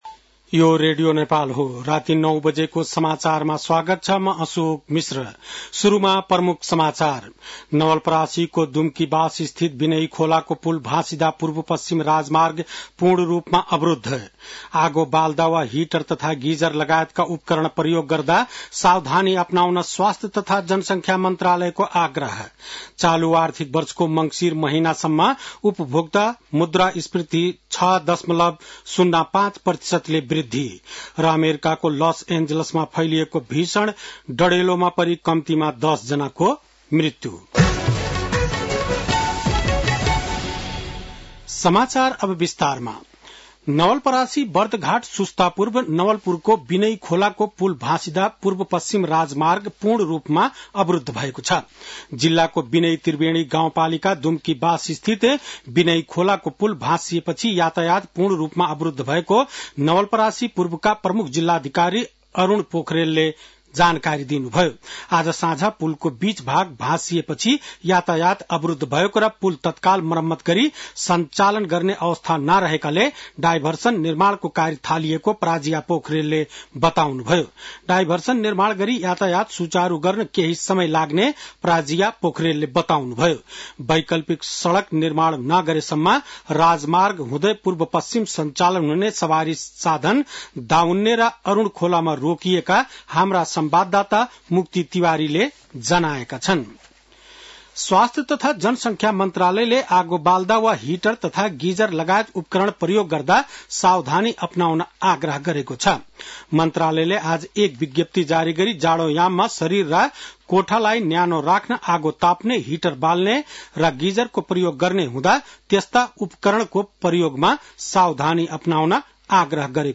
बेलुकी ९ बजेको नेपाली समाचार : २७ पुष , २०८१